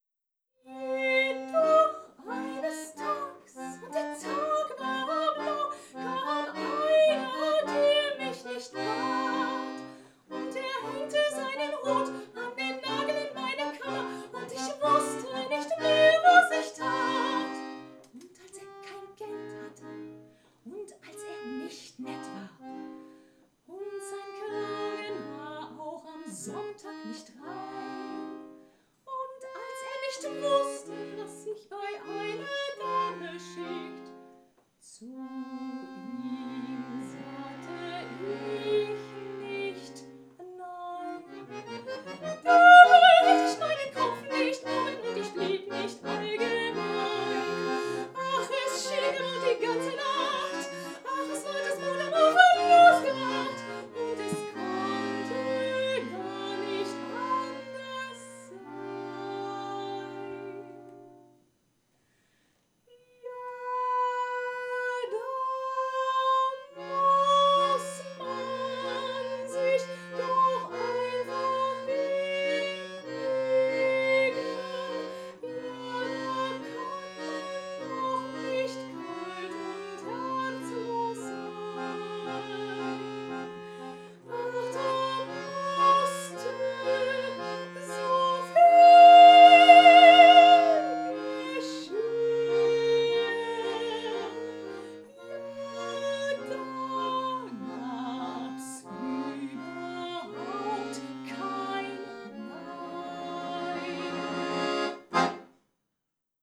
Ein Komponist und eine Stimme und ein Akkordeon.
Gesang
Akkordeon
Live